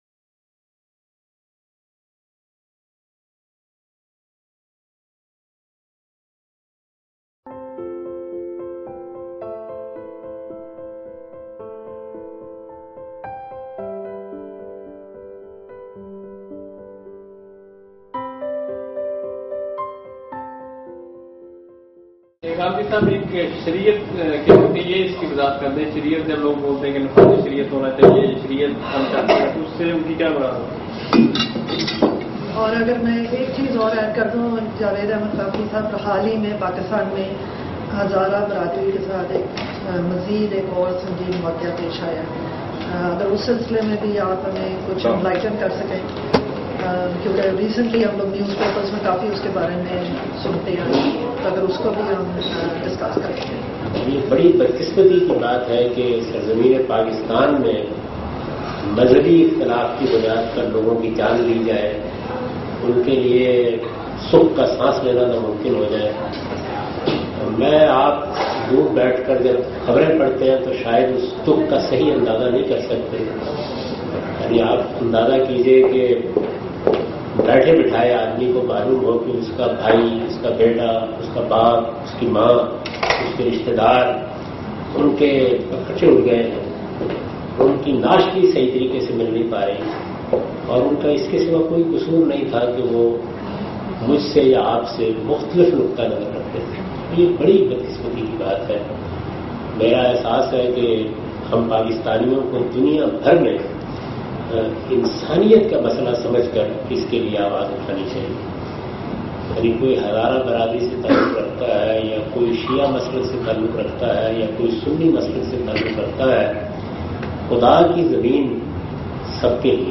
11. Question and Answer (Miscellaneous)
This session was recorded in Australia in January 2014. The visit and lectures were organized by Al-Mawrid Australia.